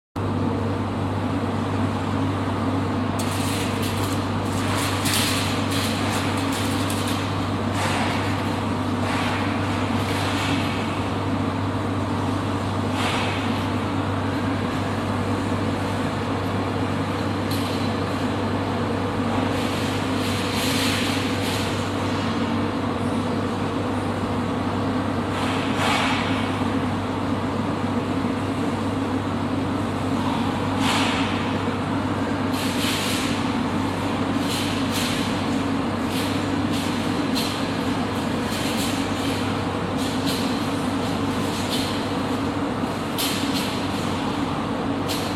Sonneries » Sons - Effets Sonores » bruitage usine bgm